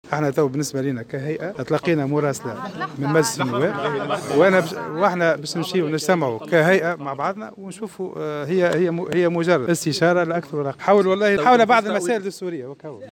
تصريح خاطف